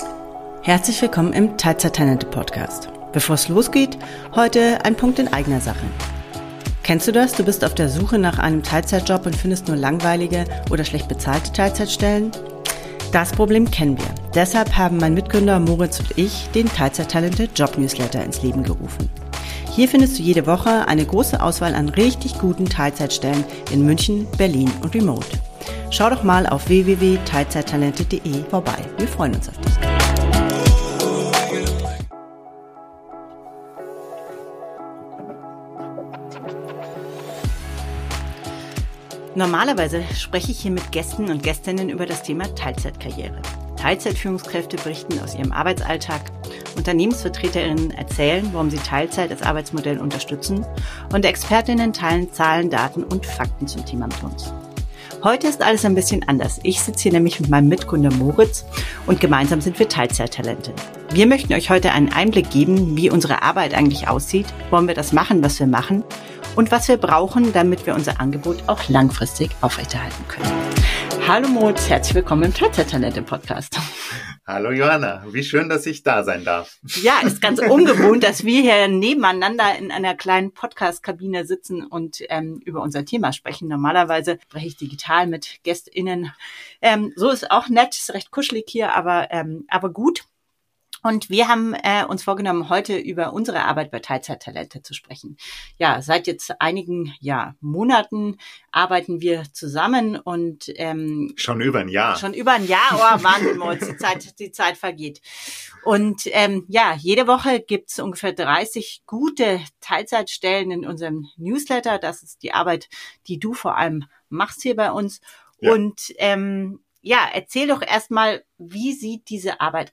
Normalerweise spreche ich hier mit Gästen und Gästinnen über das Thema Teilzeitkarriere.